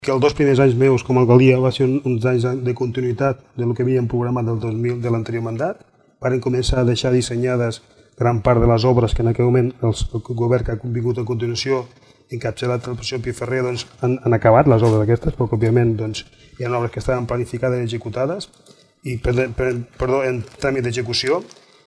Debat Electoral Palafrugell 2019